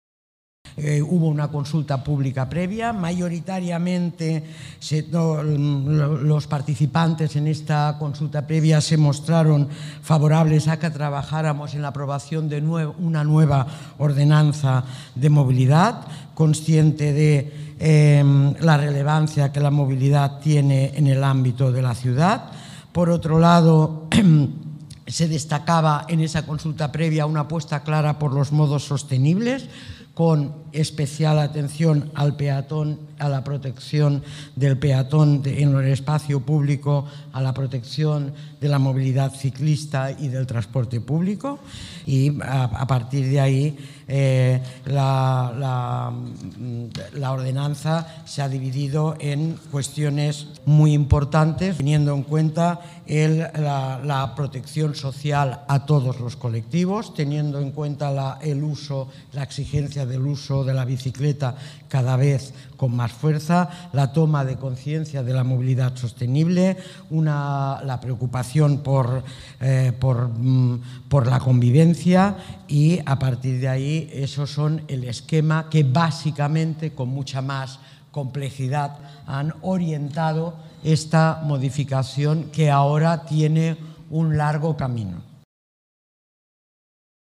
Inés Sabanés habla sobre la consulta pública previa